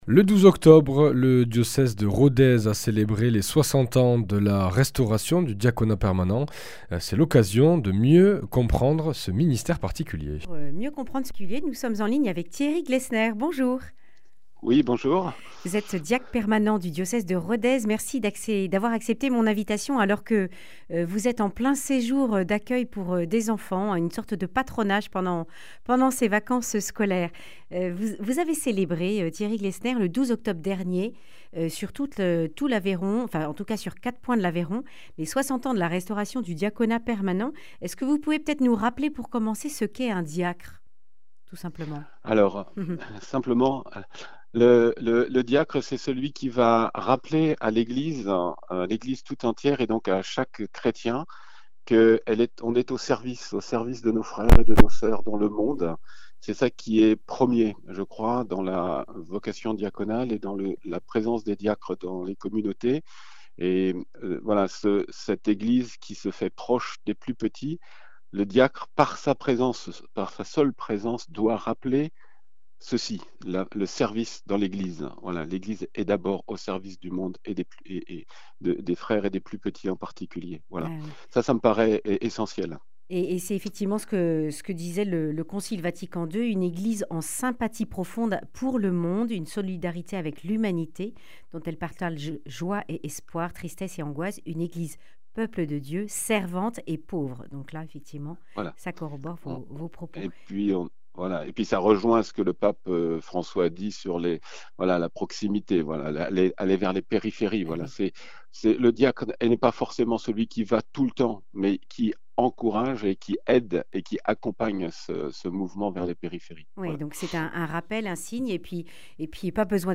Accueil \ Emissions \ Information \ Régionale \ Le grand entretien \ De quoi le diacre est-il signe ?